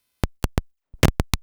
clicks